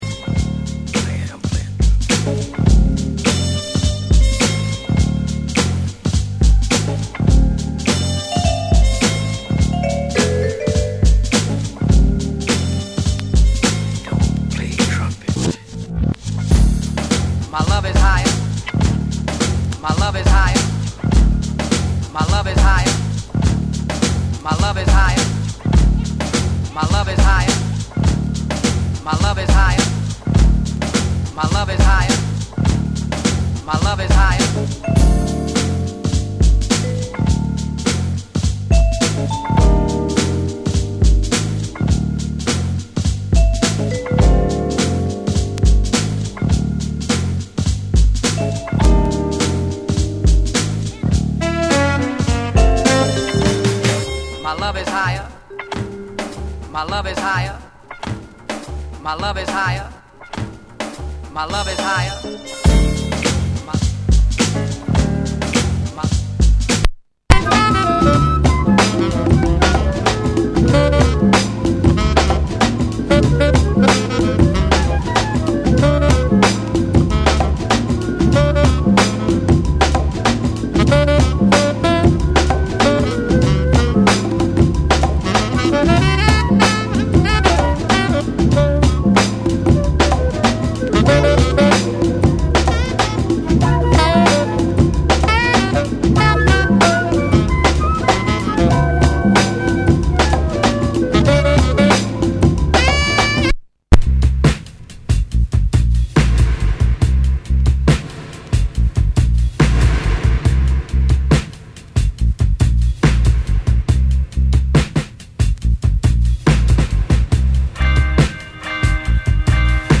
Top downtempo breaks.